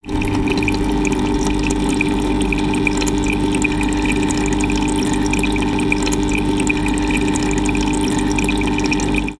fishtank.wav